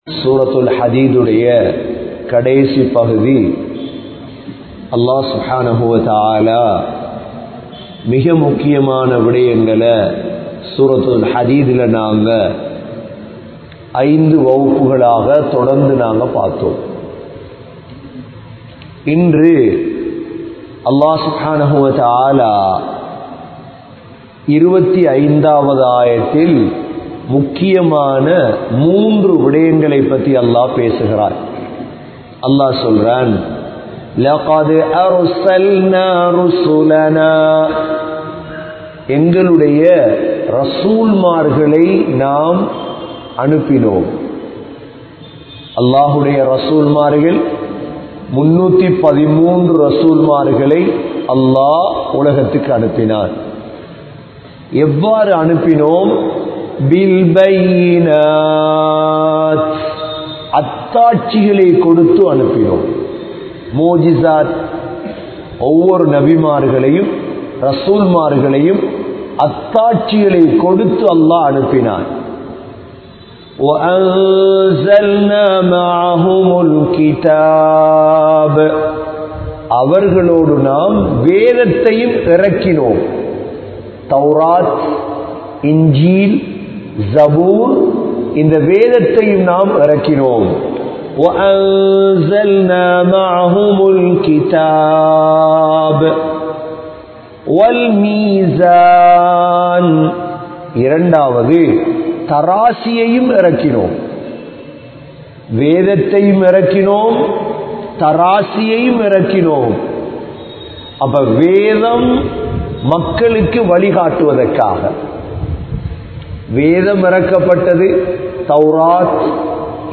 Surah AL Hadid(Part 06) Thafseer Lesson 117 | Audio Bayans | All Ceylon Muslim Youth Community | Addalaichenai